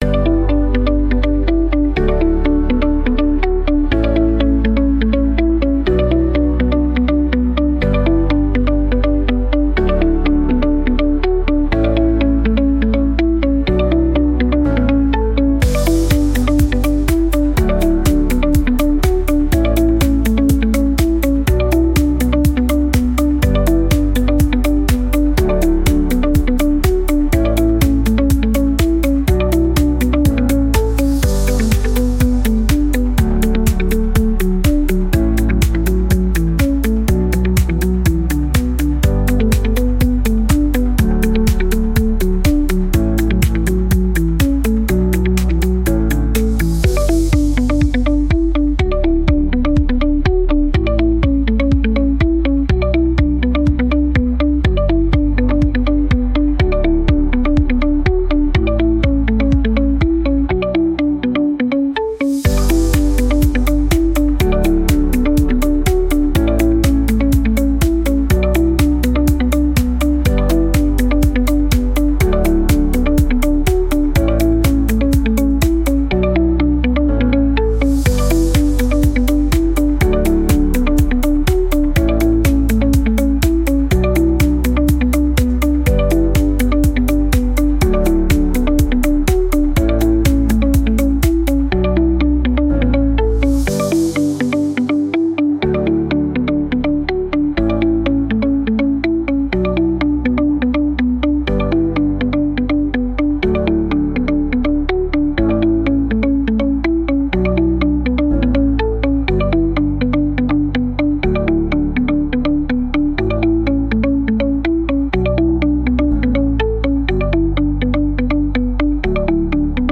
• Качество: Хорошее
• Категория: Детские песни / Музыка детям 🎵